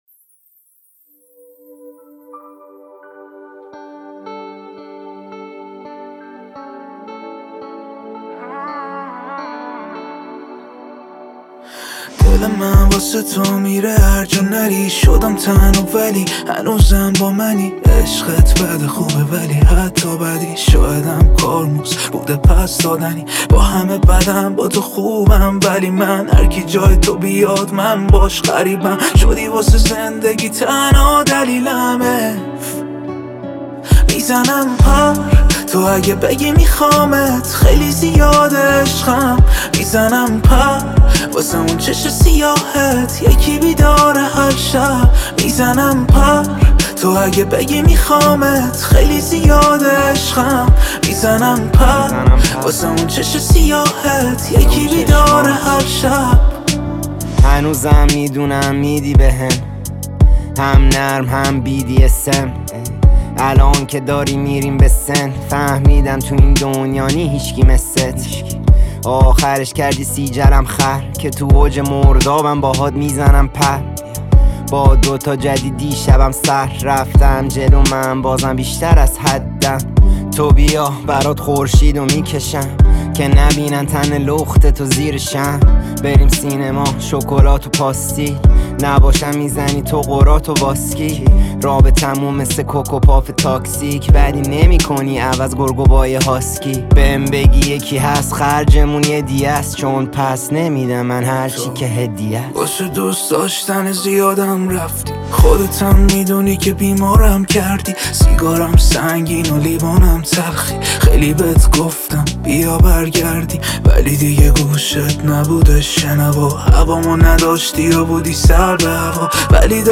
a fresh mix of pop and Persian rap
اهنگ رپ موزیک